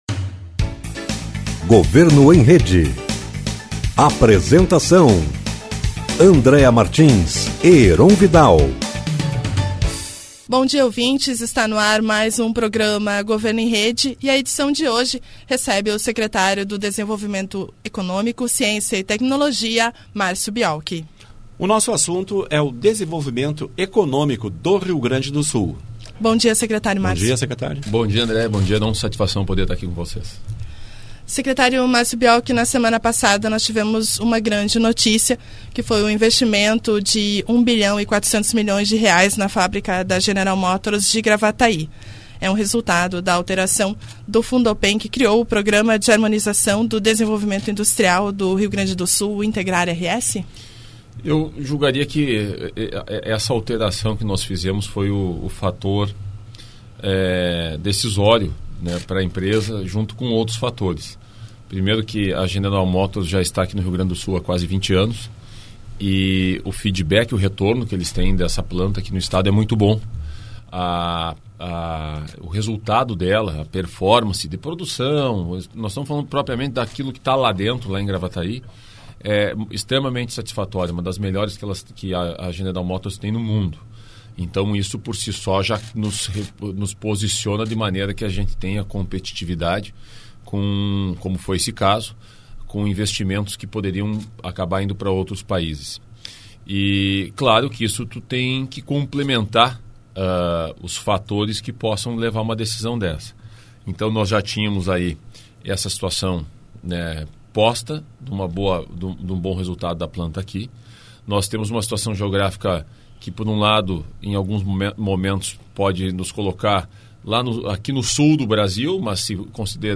Márcio Biolchi em entrevista na Rádio Piratini